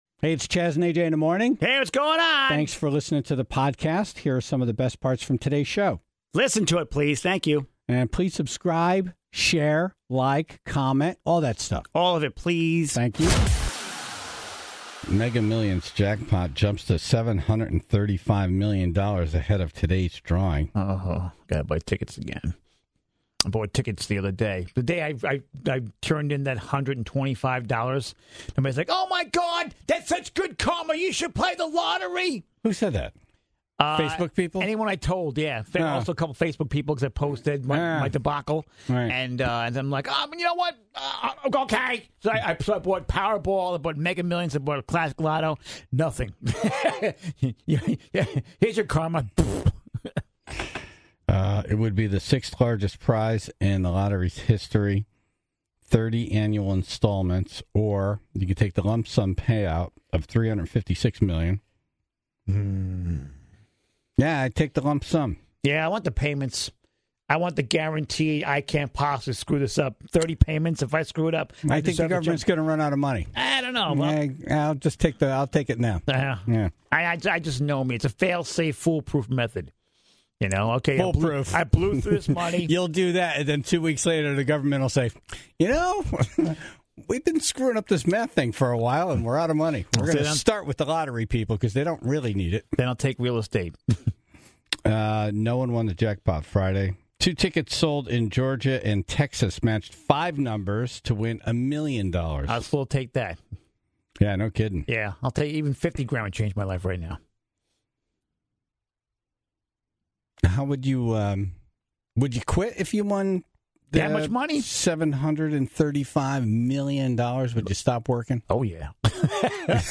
(5:20) Connecticut is considering stronger penalties for passing school buses. The Tribe called in with their personal and observed traffic encounters with school buses.